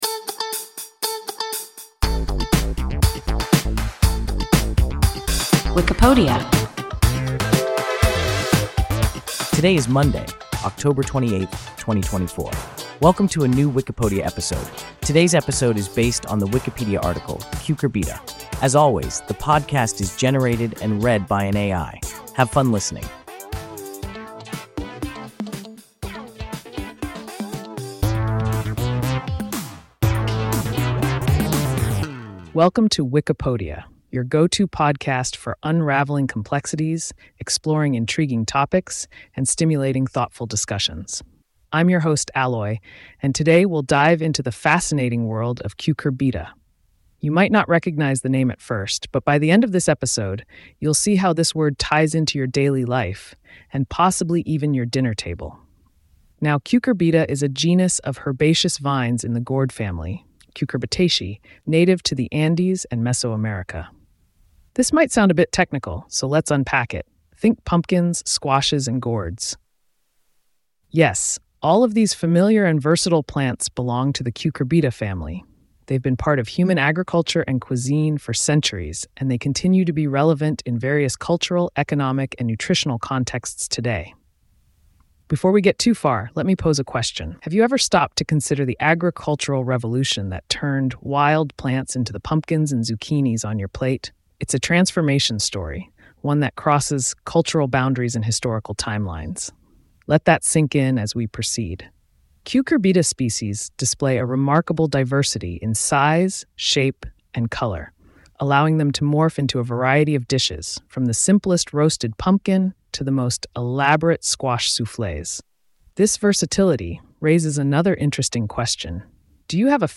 Cucurbita – WIKIPODIA – ein KI Podcast